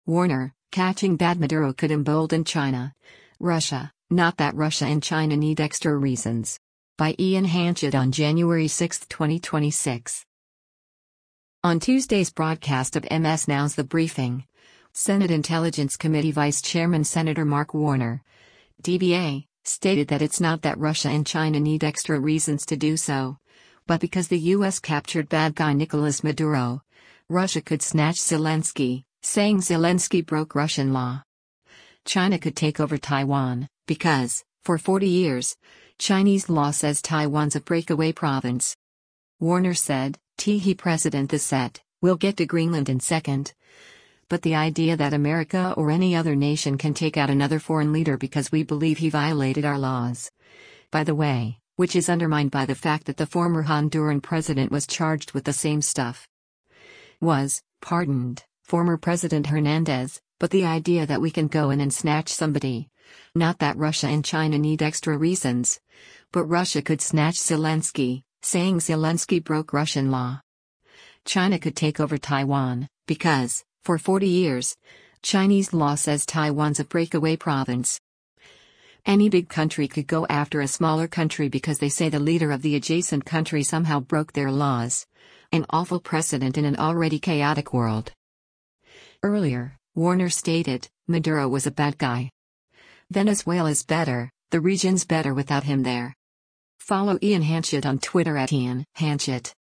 On Tuesday’s broadcast of MS NOW’s “The Briefing,” Senate Intelligence Committee Vice Chairman Sen. Mark Warner (D-VA) stated that it’s “not that Russia and China need extra reasons” to do so, but because the U.S. captured “bad guy” Nicolas Maduro, “Russia could snatch Zelensky, saying Zelensky broke Russian law. China could take over Taiwan, because, for 40 years, Chinese law says Taiwan’s a breakaway province.”